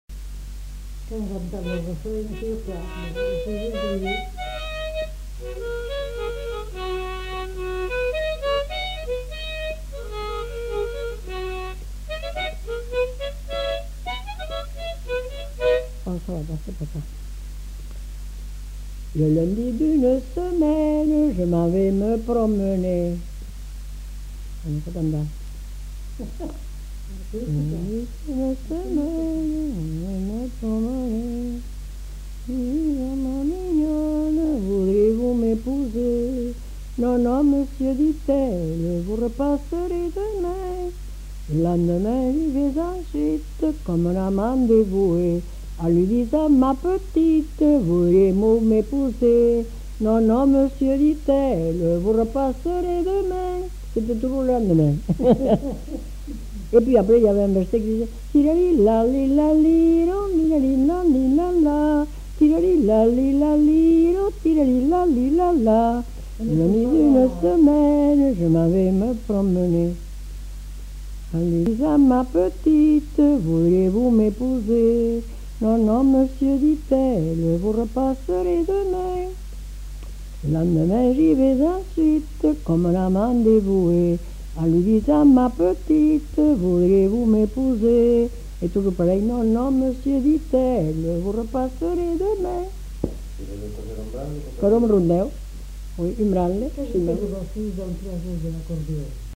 Aire culturelle : Haut-Agenais
Lieu : Cancon
Genre : chant
Effectif : 1
Type de voix : voix de femme
Production du son : chanté
Danse : scottish
Notes consultables : Elle le jour d'abord à l'harmonica.